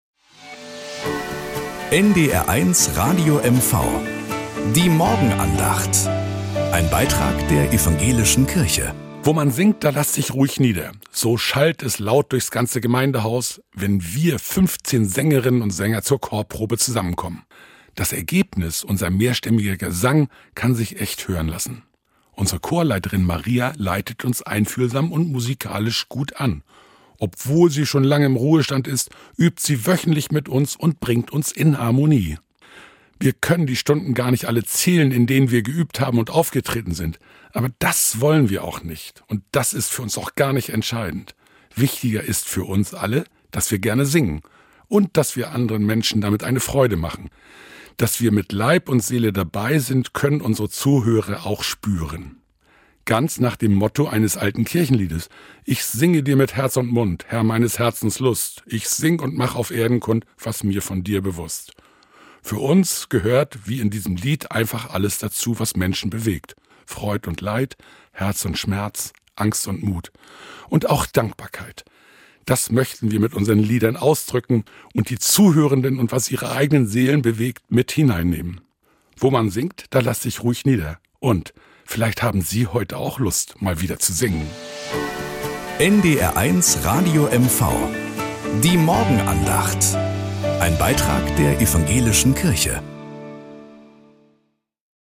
Nachrichten aus Mecklenburg-Vorpommern - 27.02.2025